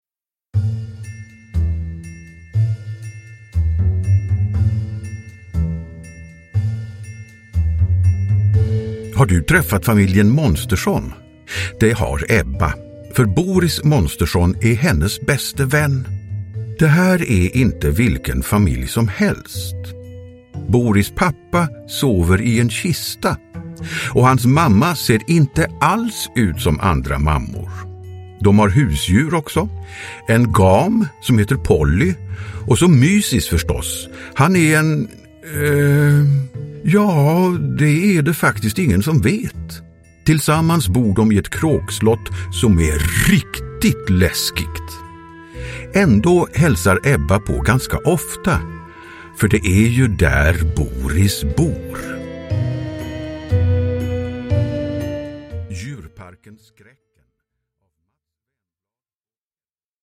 Djurparken Skräcken – Ljudbok – Laddas ner